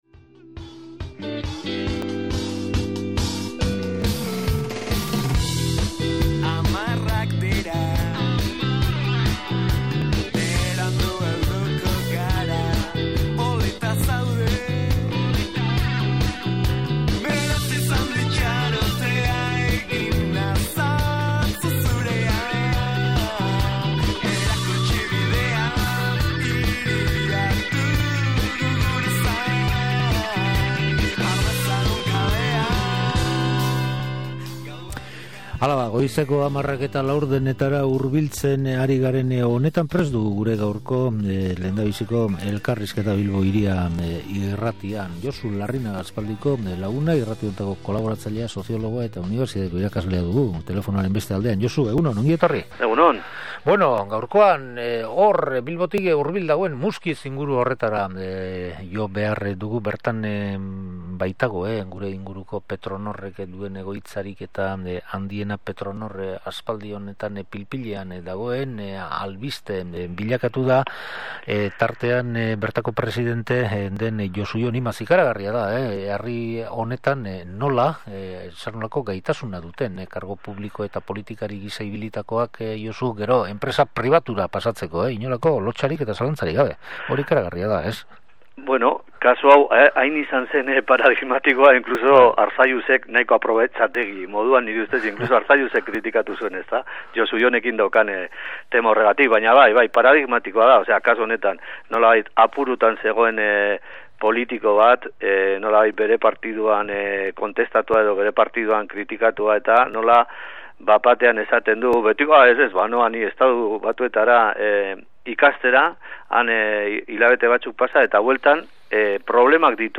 SOLASALDIA: Petronorreko Coke planta | Bilbo Hiria irratia